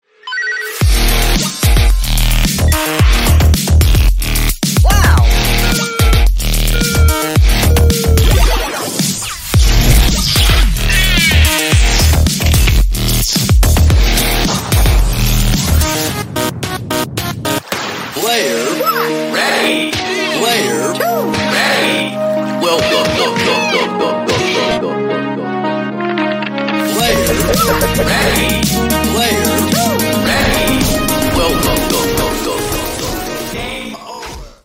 Электроника
без слов